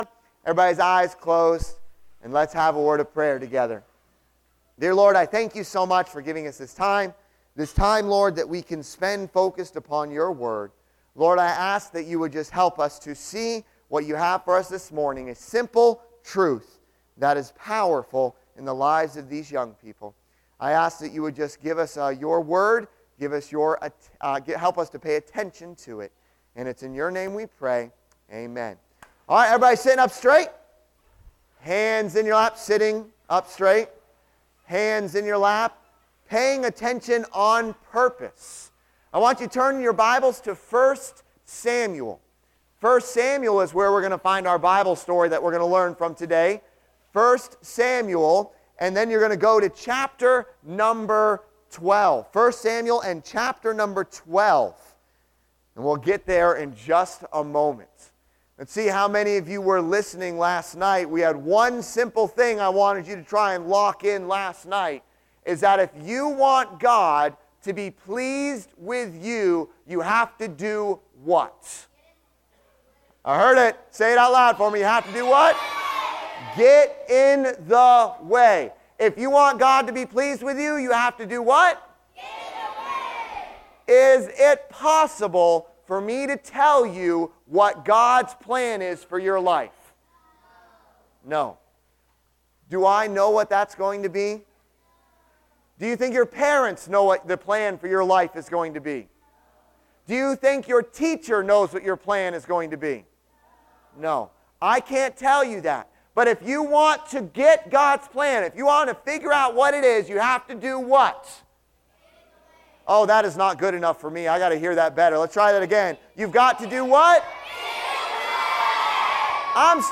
Service Type: Junior Camp